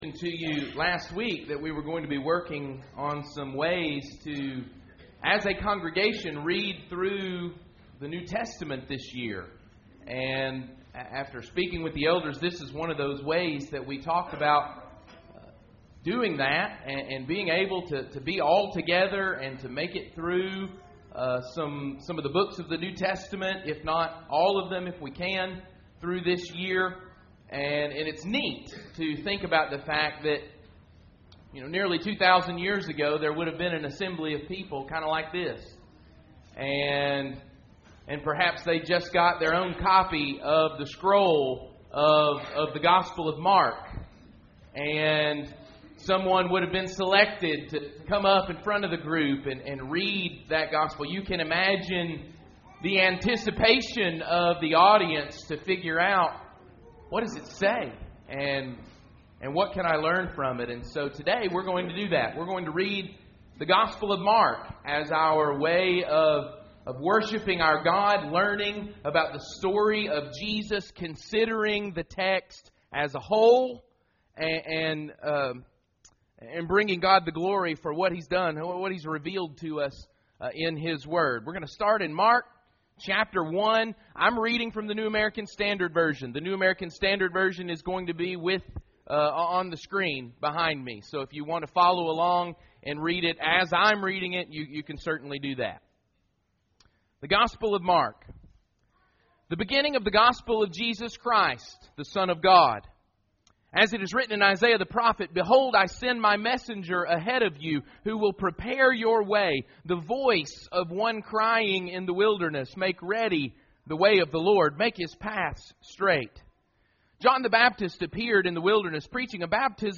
A Reading of the Gospel of Mark, 1 – 8